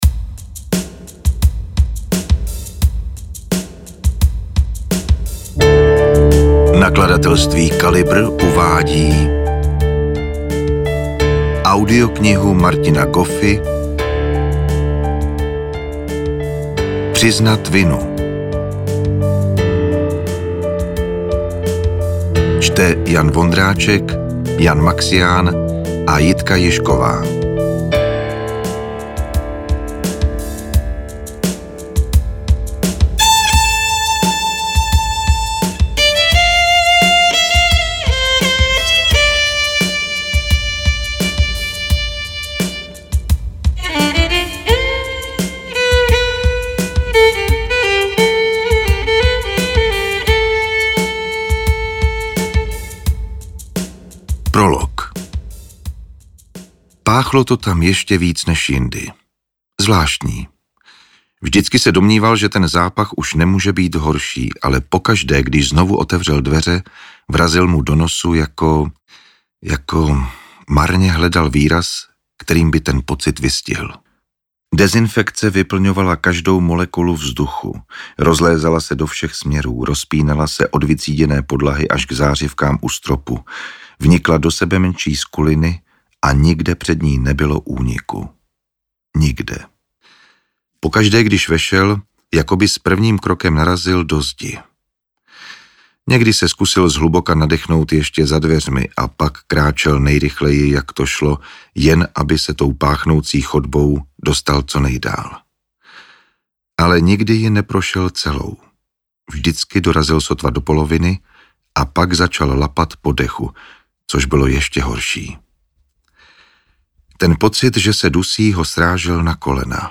audiokniha_priznat_vinu_ukazka.mp3